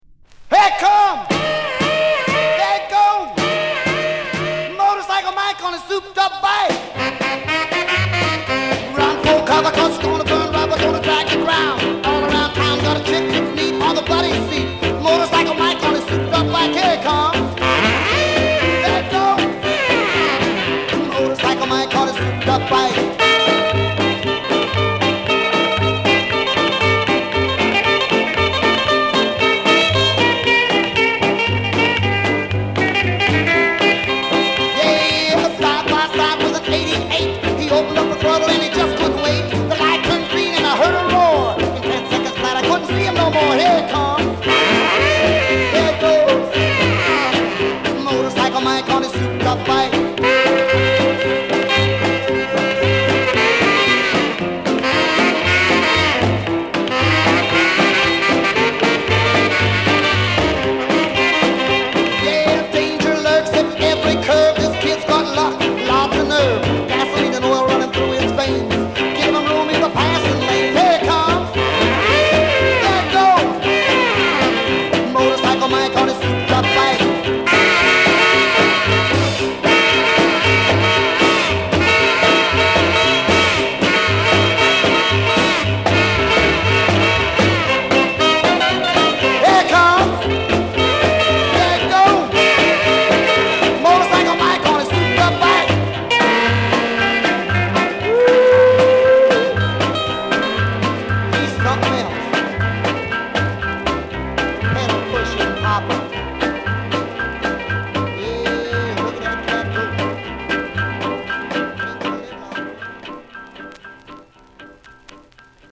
Here Comesの掛け声で始まり、ラストまで一気に駆け抜ける様は手に汗握る興奮を覚える。